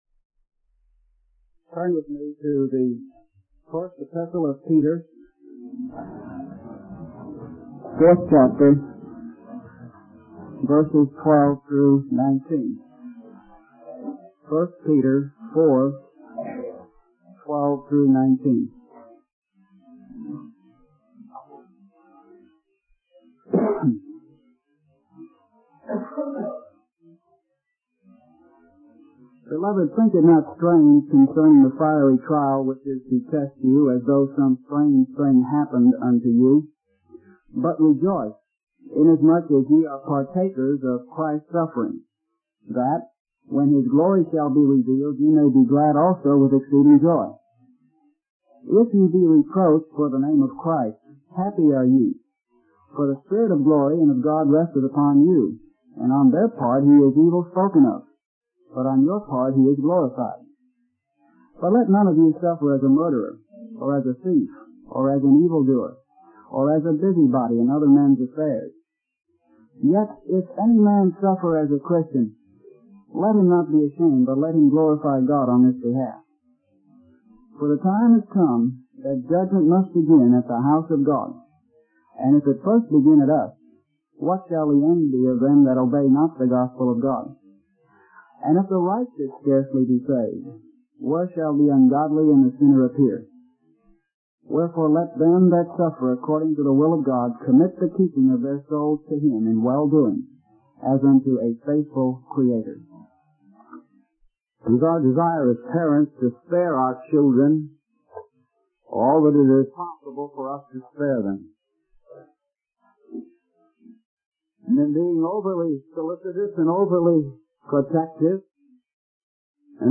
In this sermon, the preacher focuses on the theme of rejoicing in the midst of suffering, specifically the fiery trials mentioned in 1 Peter 4:12.